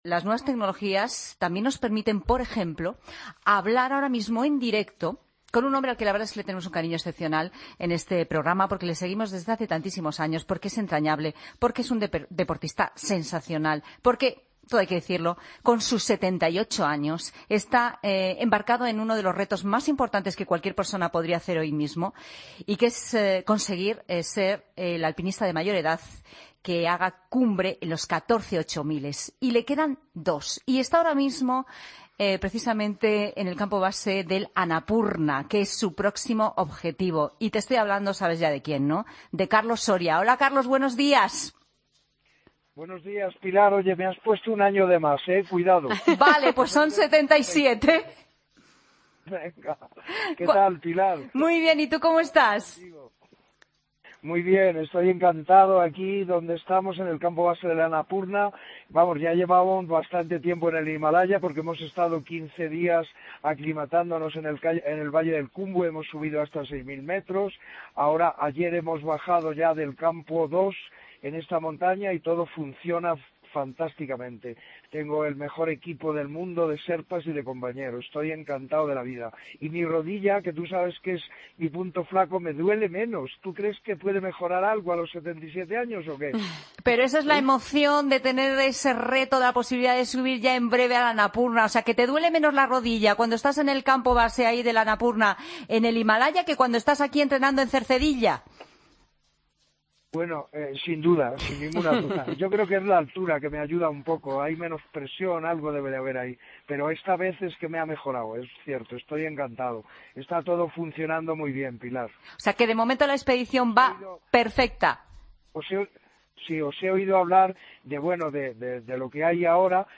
Redacción digital Madrid - Publicado el 24 mar 2016, 14:56 - Actualizado 18 mar 2023, 11:38 1 min lectura Descargar Facebook Twitter Whatsapp Telegram Enviar por email Copiar enlace Hablamos con Carlos Soria, el alpinista de 77 años, que se encuentra en el campo base del Annapurna.